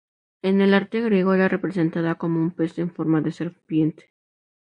ser‧pien‧te
Pronounced as (IPA)
/seɾˈpjente/